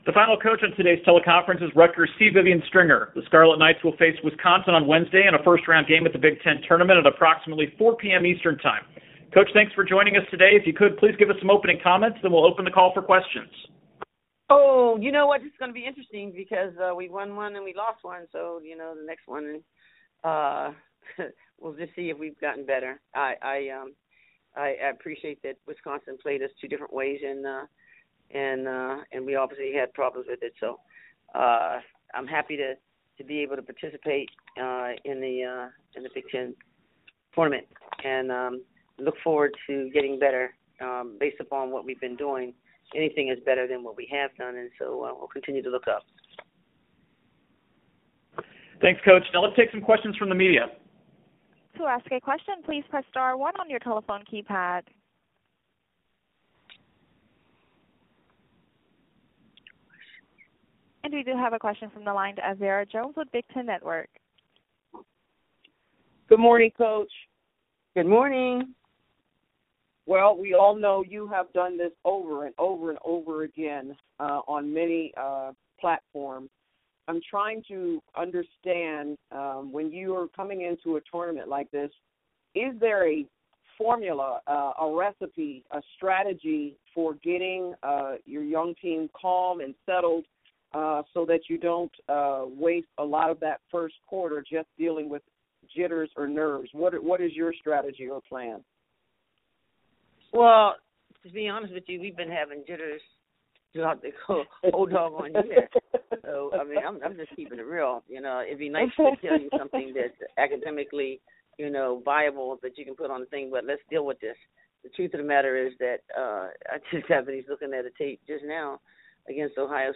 Audio: Big Ten Women's Basketball Tournament Teleconference - Big Ten Network
2-27-rutgers-c-vivian-stringer.mp3